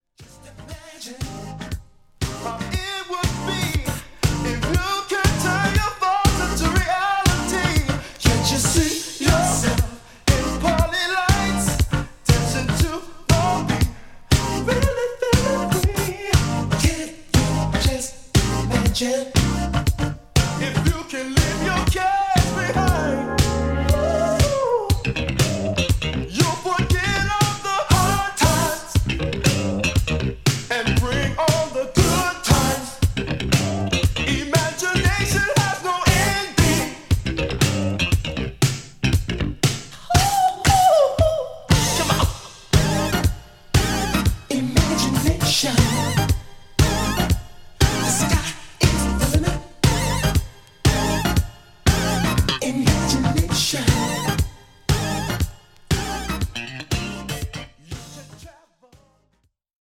ホーム ｜ SOUL / FUNK / RARE GROOVE / DISCO > SOUL